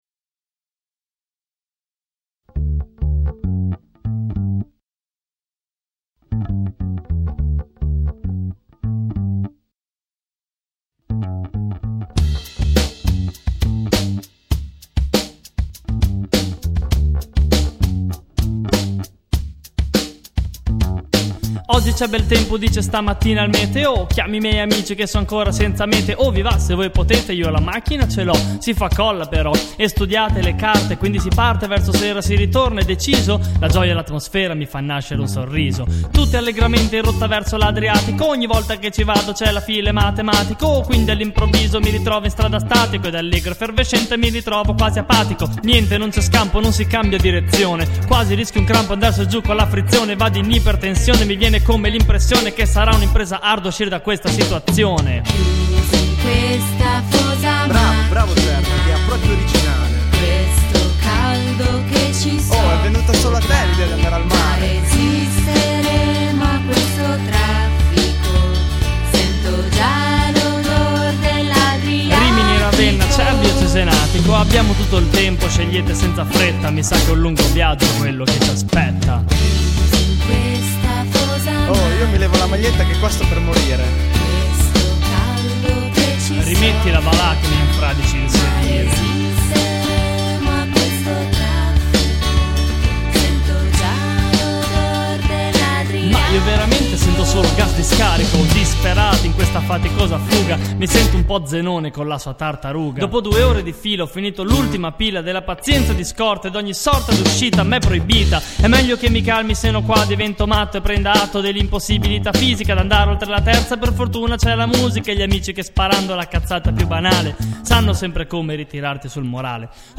GenereHip Hop / Rap